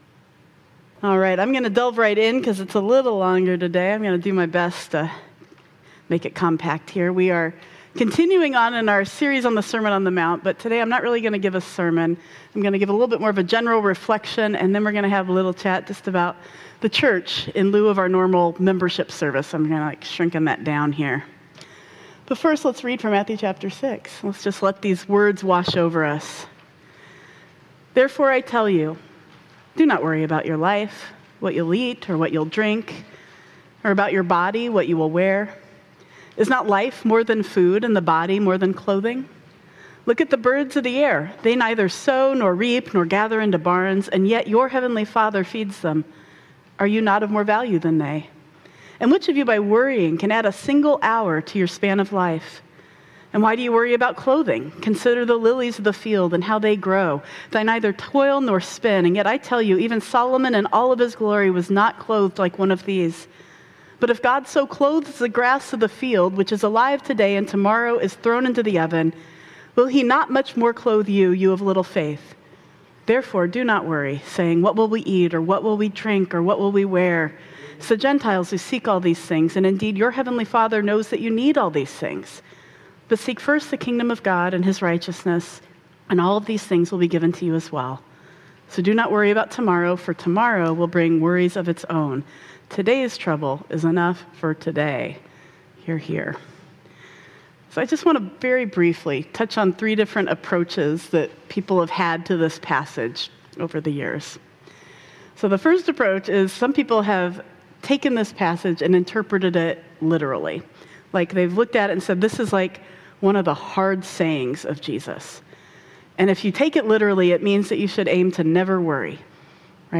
02 Nov 2025 | Sermon On the Mount: Don’t Worry ’Bout a Thing - Blue Ocean Church Ann Arbor